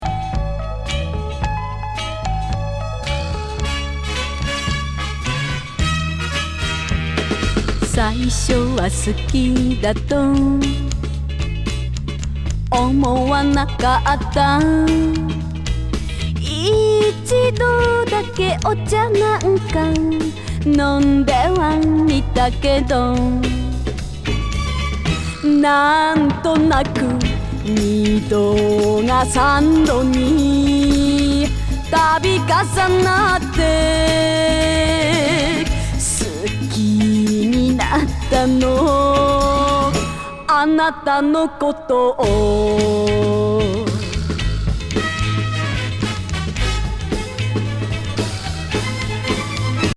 ファンキー・オーケストラ+セクシーお色気歌謡